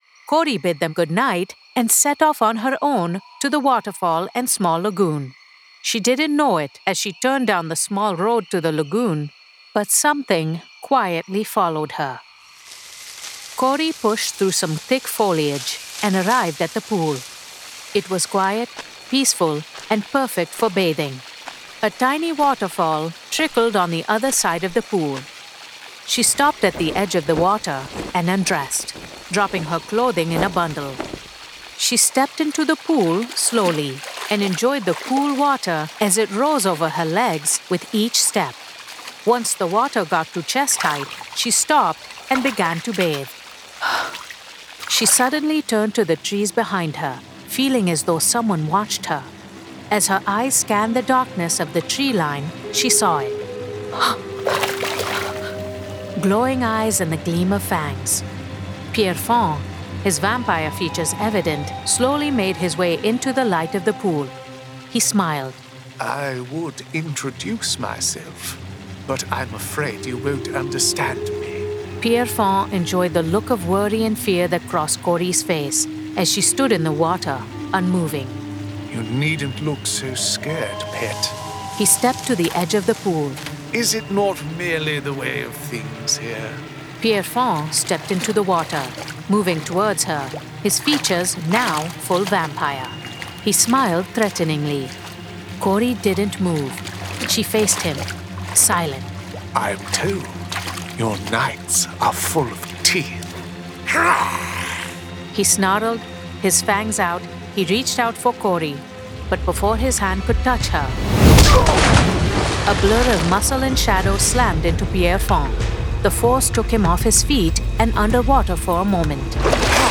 Full Cast. Cinematic Music. Sound Effects.
[Dramatized Adaptation]
Adapted directly from the graphic novel and produced with a full cast of actors, immersive sound effects and cinematic music.